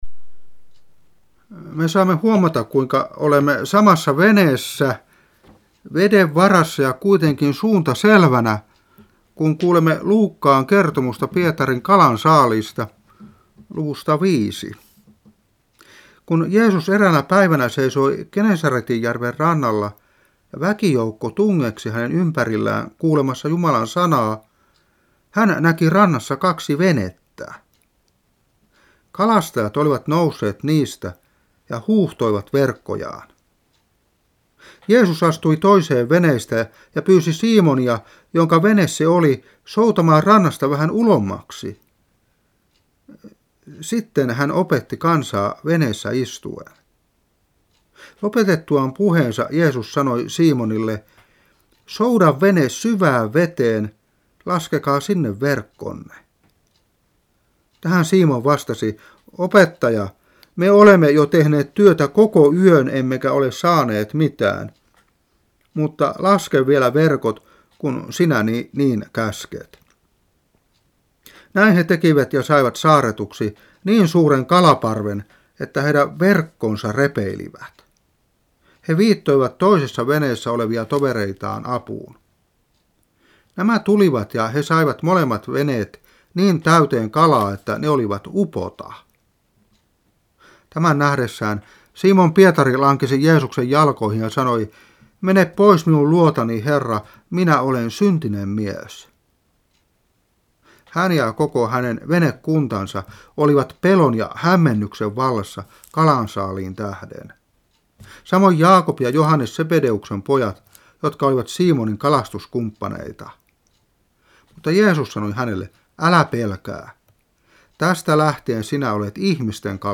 Saarna 1993-6. Luuk.5:1-5.